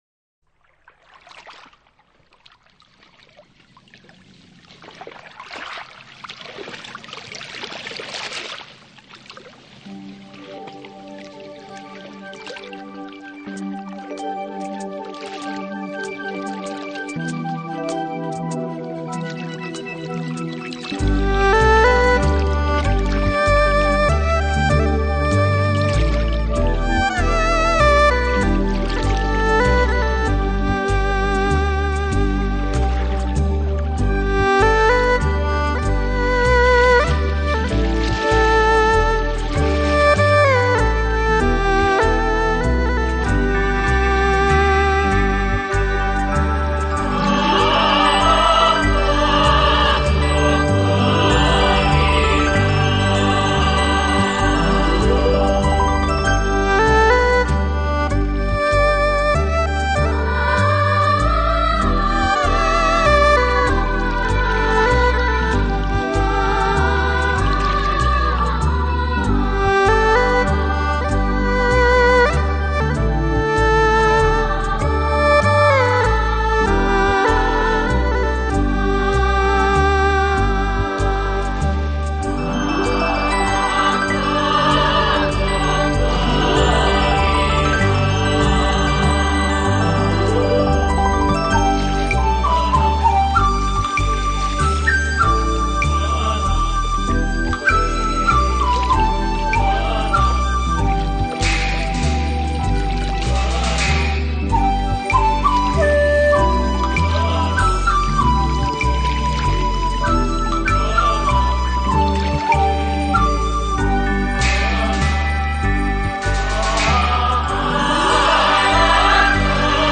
因此總愛在巴烏、竹筒琴奏出的迷人音韻中，跳起婀娜的孔雀舞；